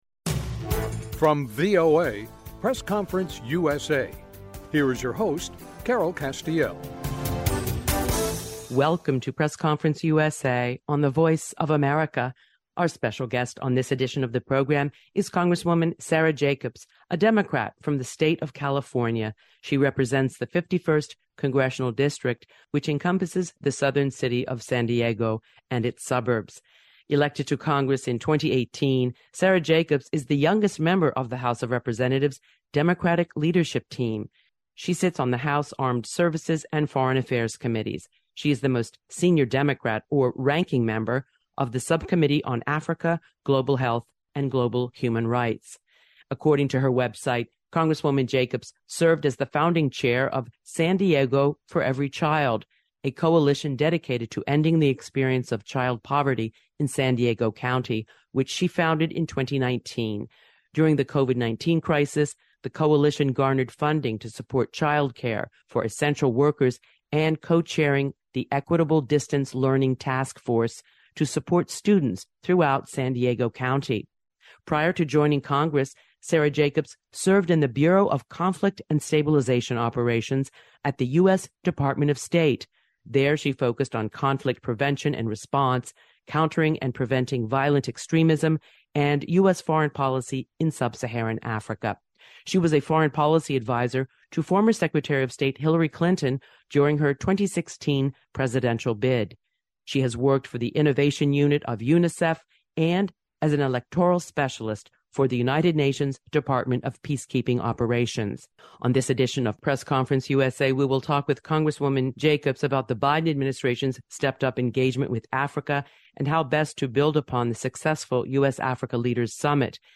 A Conversation with Congresswoman Sara Jacobs, D, CA about US – Africa Policy